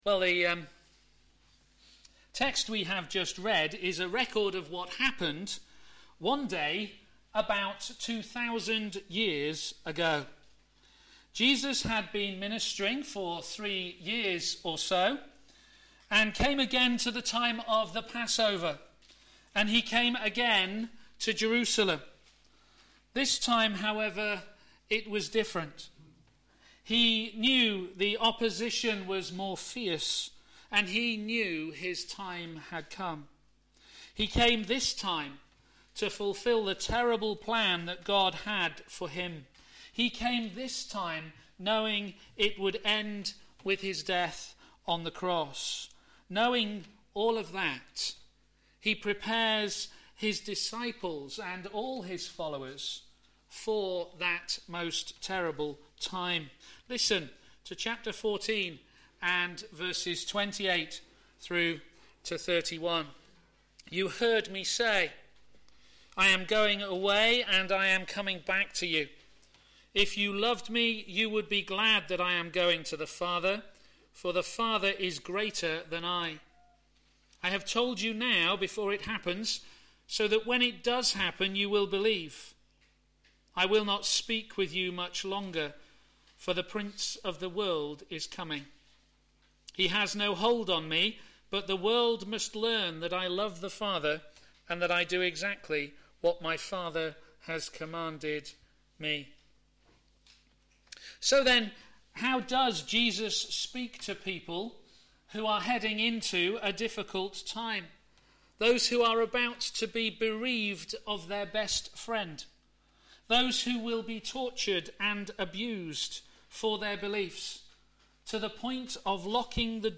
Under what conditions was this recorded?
Media for a.m. Service